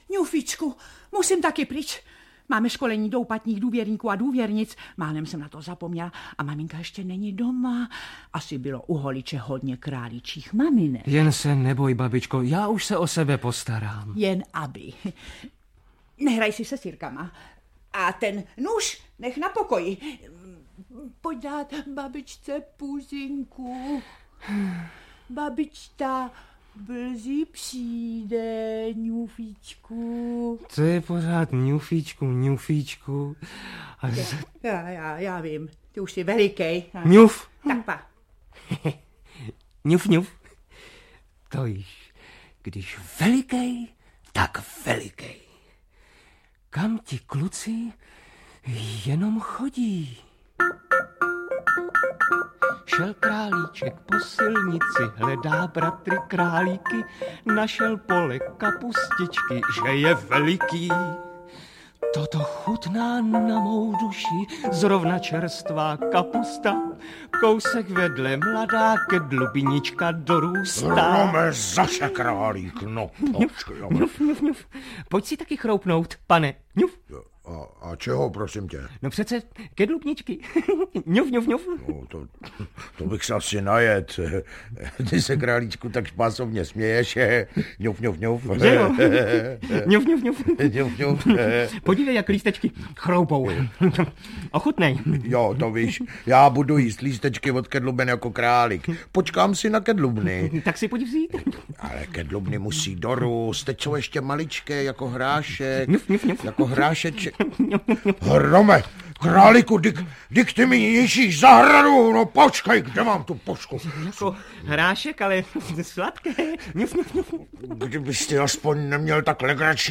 Ukázka z knihy
Osmý díl miniserie Album pohádek "Supraphon dětem" představuje sedm pohádek z archivu Supraphonu ze 70. let - mimo Psí pohádku, která je vyprávěná, jsou všechny dramatizované.